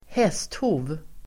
Uttal: [²h'es:tho:v]